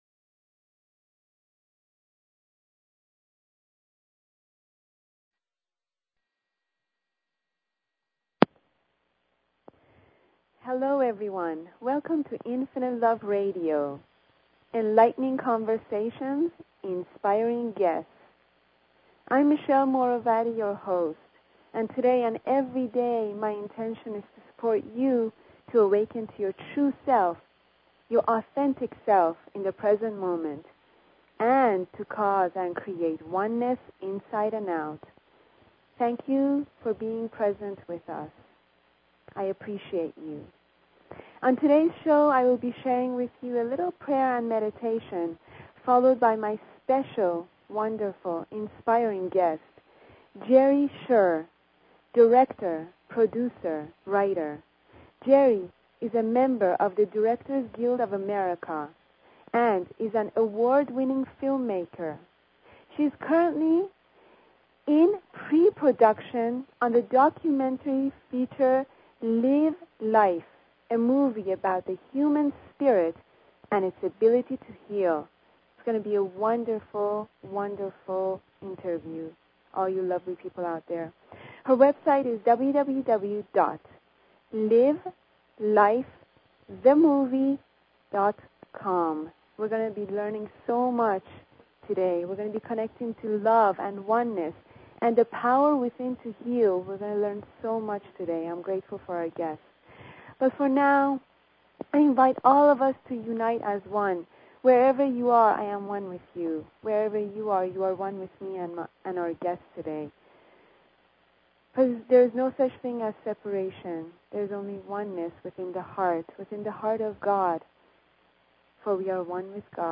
Talk Show Episode, Audio Podcast, Infinite_Love_Radio and Courtesy of BBS Radio on , show guests , about , categorized as